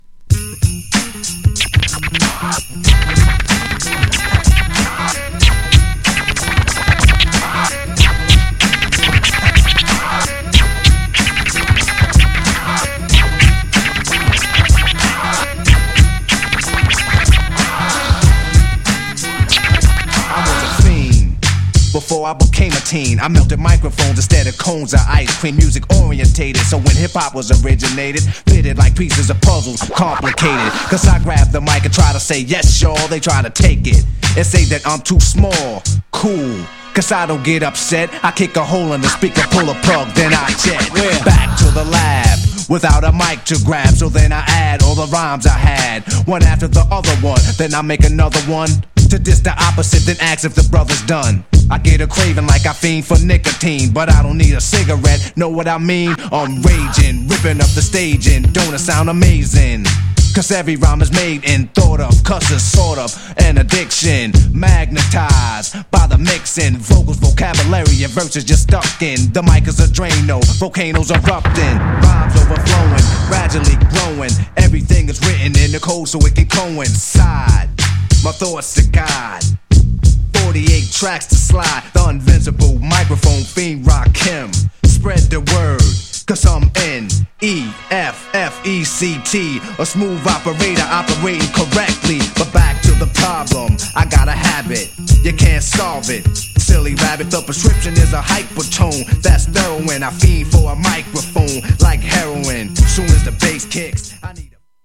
GENRE Hip Hop
BPM 101〜105BPM
# 打ち付ける様なビートがアツイ # 間違いなくHIPHOP_CLASSIC